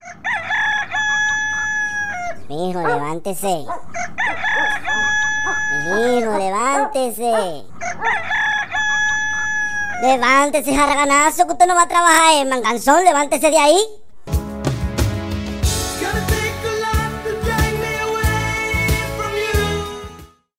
Kategorie Alarmowe